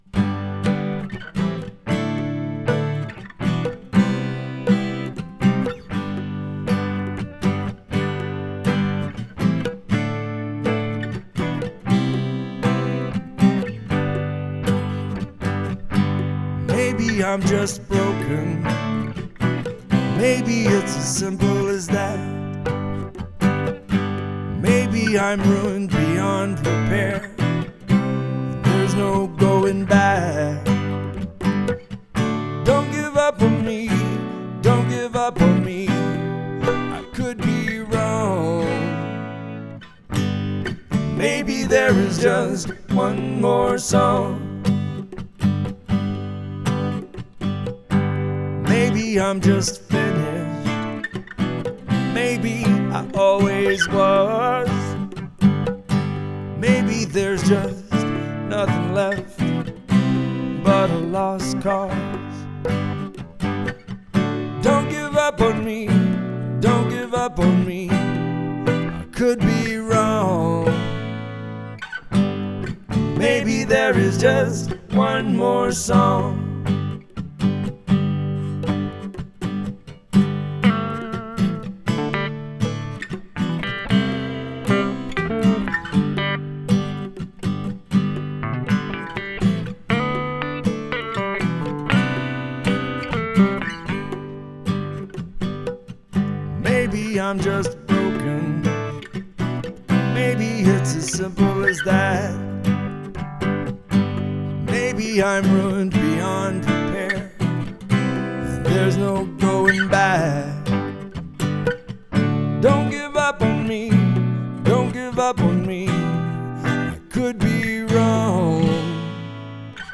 Acousticlectic Genrephobic Experimentaotic Sound